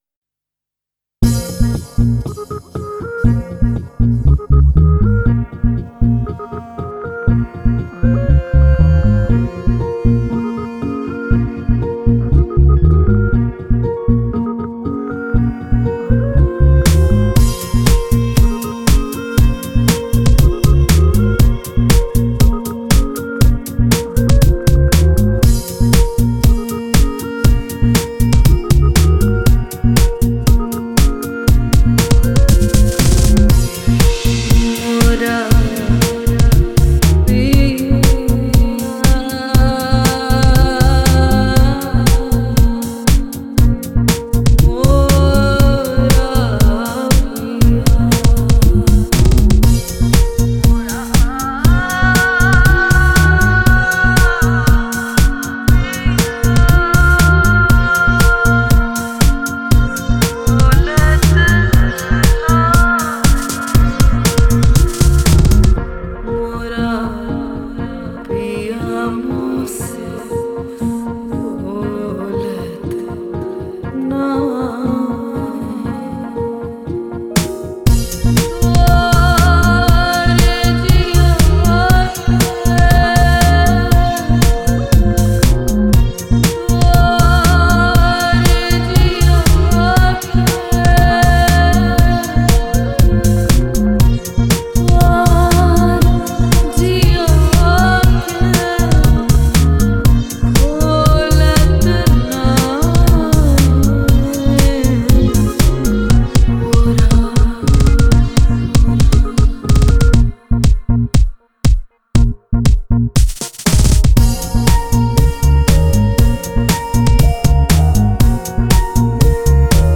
Trance Mix